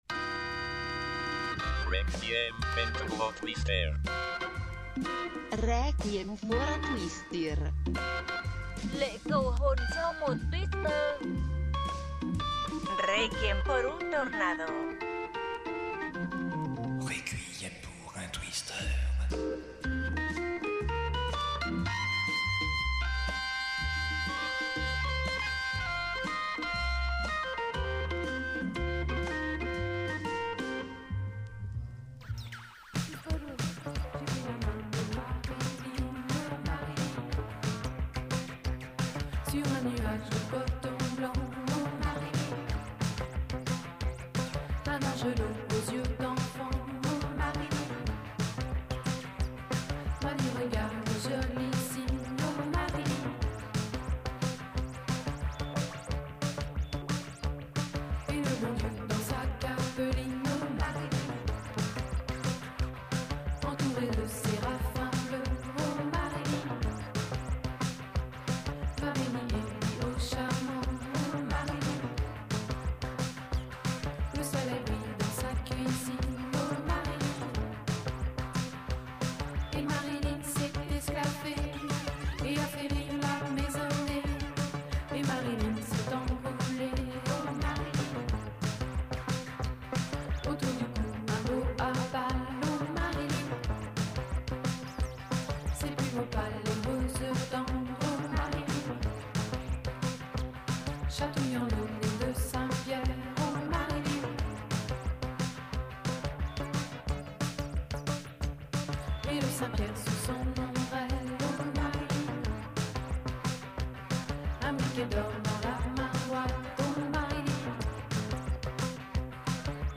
Type Mix Éclectique